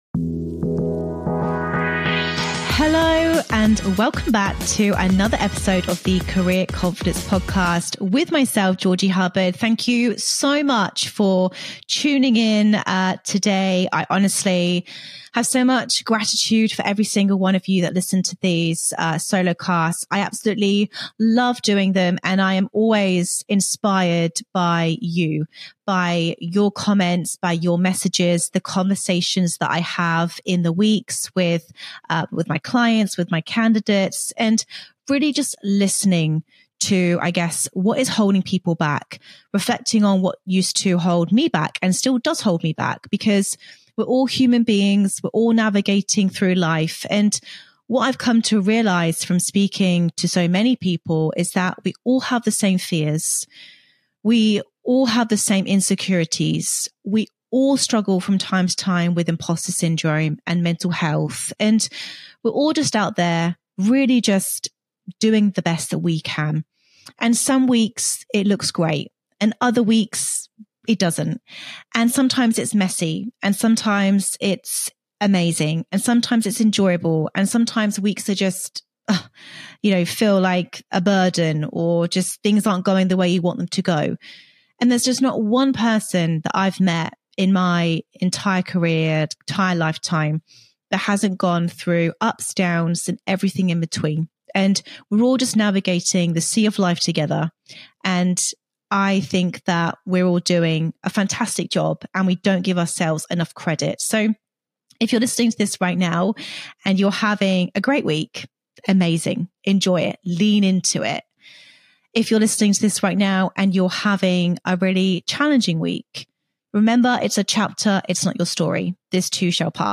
In today's solo episode